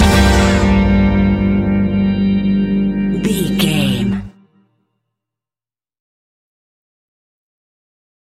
Aeolian/Minor
flamenco
latin